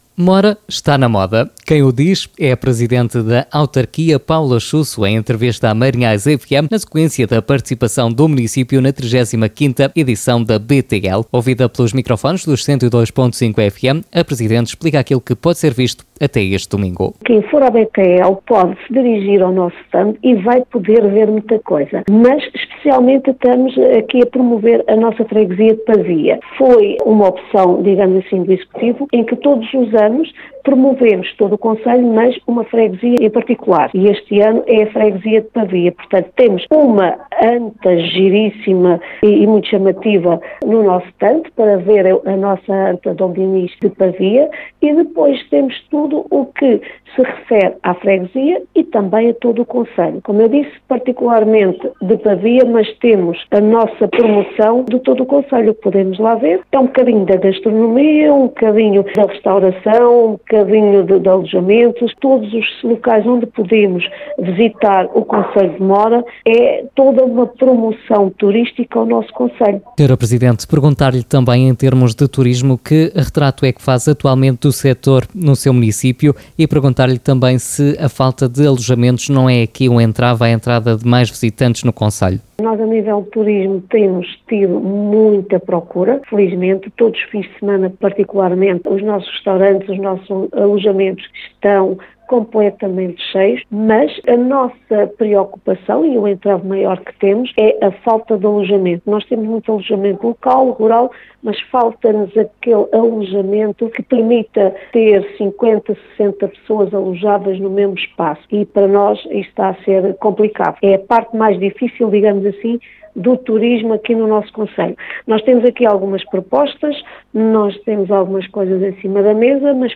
A Rádio Marinhais ouviu a Presidente da autarquia, Paula Chuço, que não hesitou em afirmar que o seu Município está na moda.